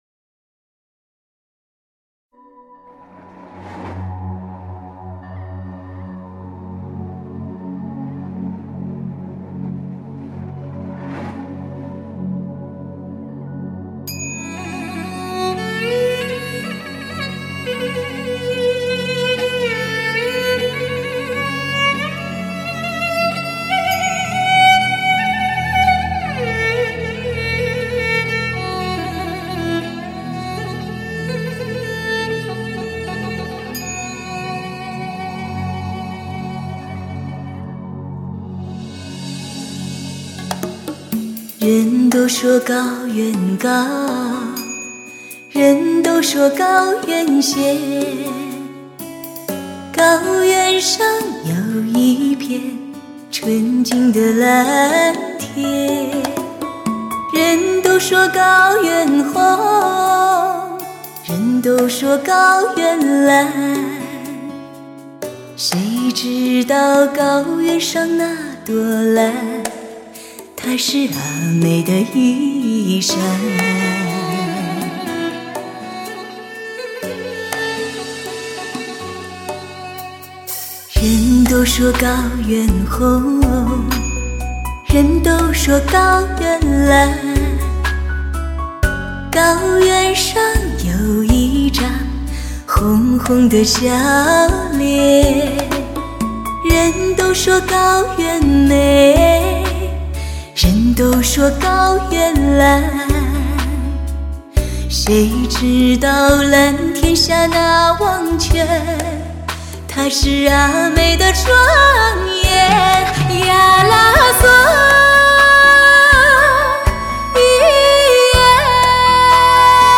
传奇再现 HIFI试音天碟 发烧典范
轻柔、纯美、复古、时尚的演奏和演唱使你流连忘返在美好的回忆之中